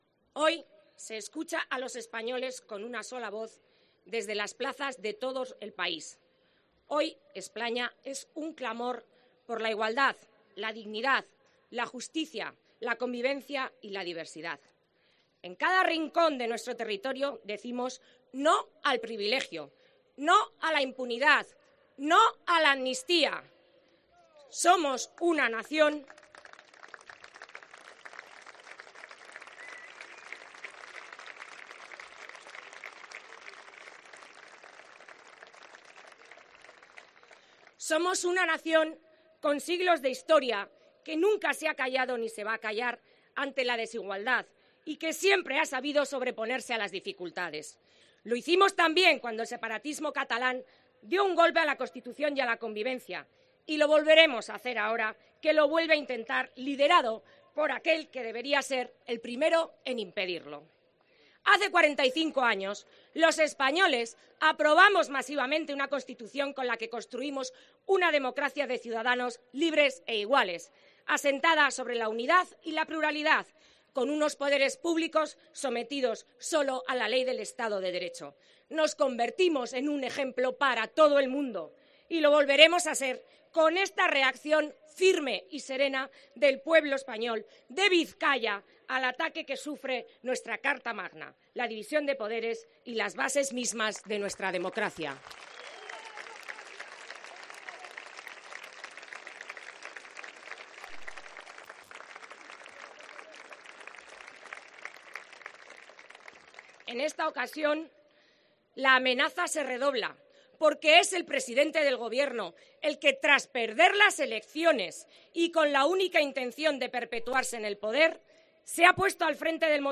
Lectura del manifiesto